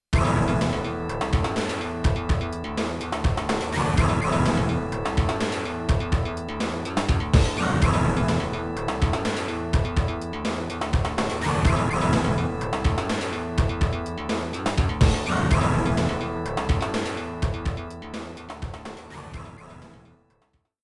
Player select theme